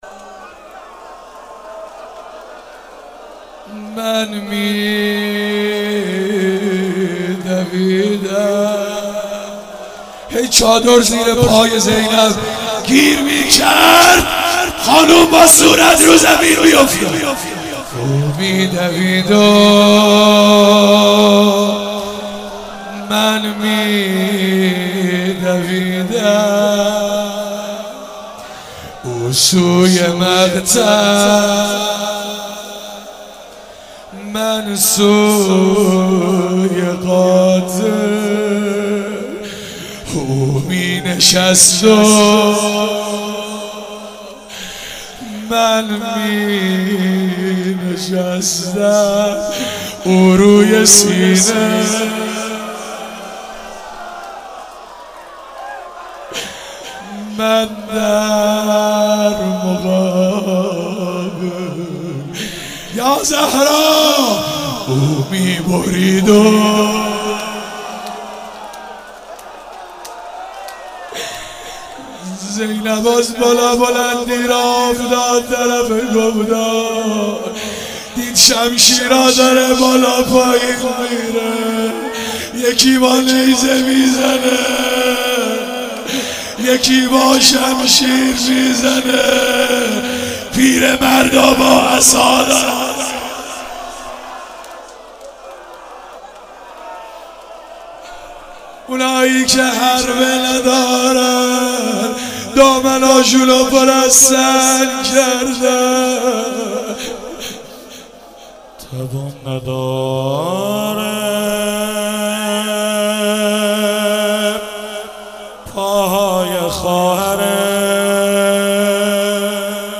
شب سوم رمضان 95
01 heiat alamdar mashhad.mp3